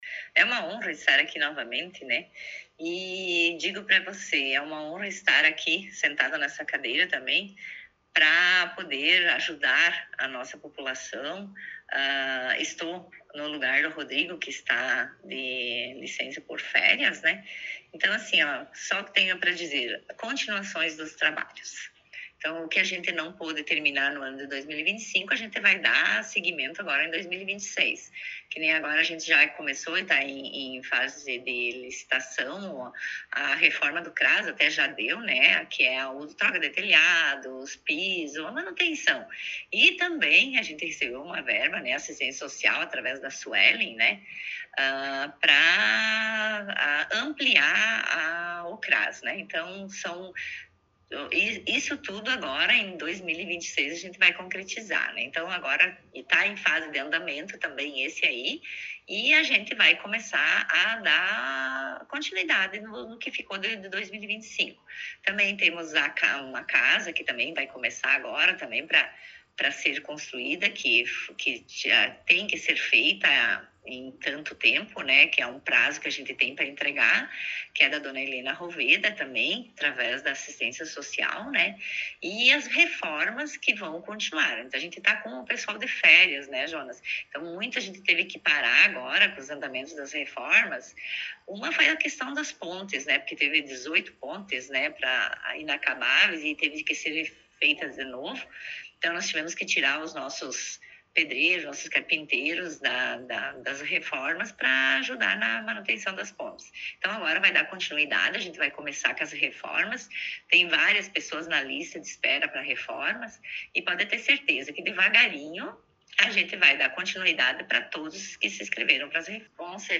Entrevista com a Prefeita em Exercício, Marta Rejane Mino